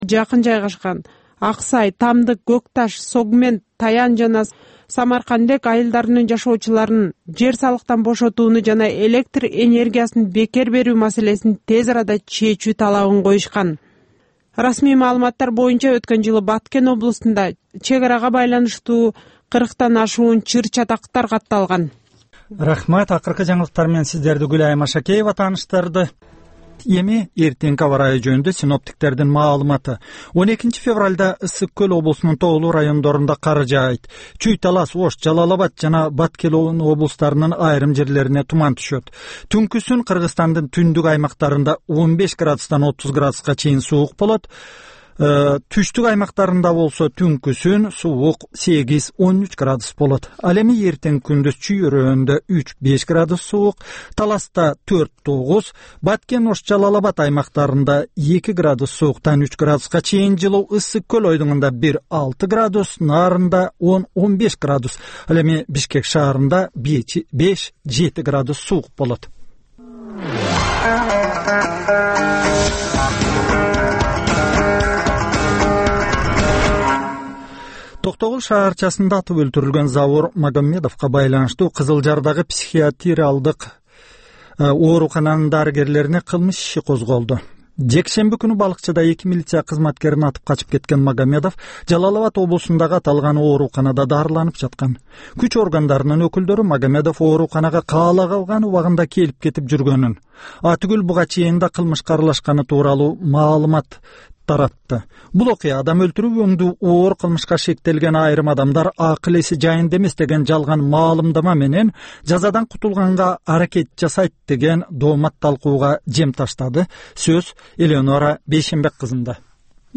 "Азаттык үналгысынын" бул кечки алгачкы жарым сааттык берүүсүнүн кайталоосу жергиликтүү жана эл аралык кабарлар, репортаж, маек, аналитикалык баян, сереп, угармандардын ой-пикирлери, окурмандардын э-кат аркылуу келген пикирлеринин жалпыламасы жана башка берүүлөрдөн турат.Ар күнү Бишкек убакыты боюнча саат 22:05тен 22:30га чейин кайталанат.